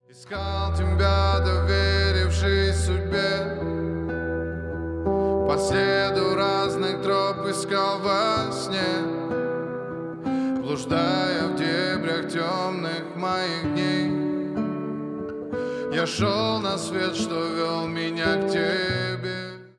грустные
поп